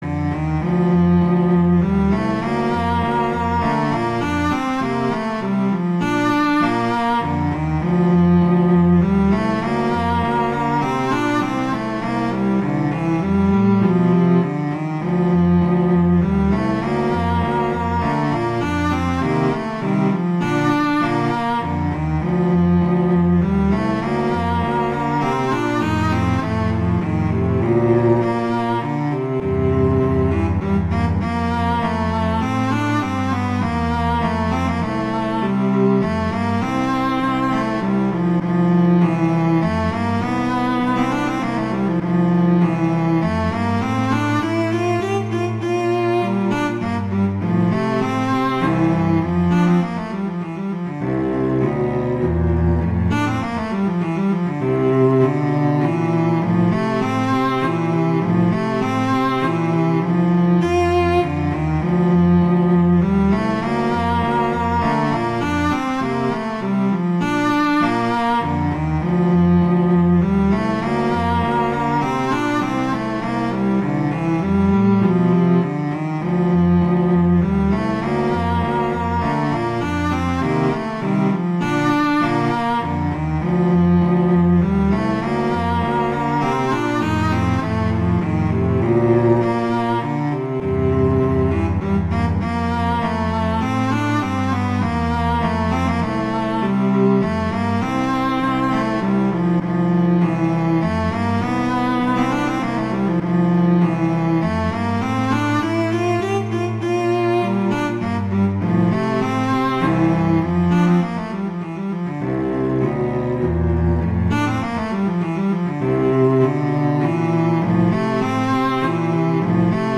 classical, french